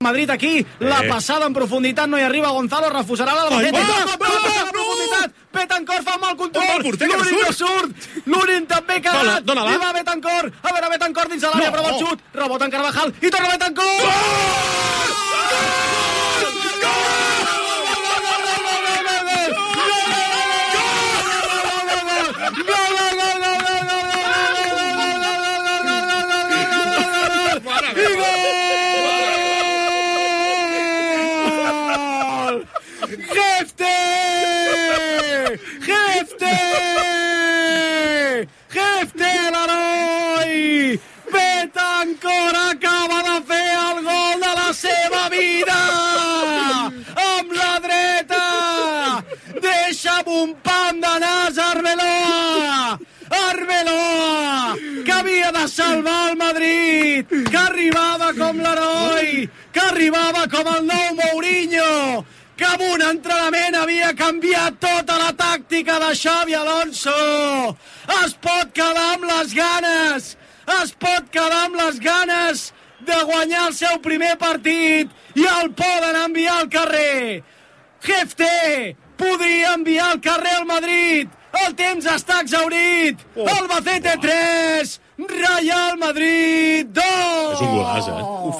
Narració del gol de Jefté al partit Albacete-Real Madrid de Copa del Rei de futbol masculí que suposa l'elininació del segon.
Esportiu
FM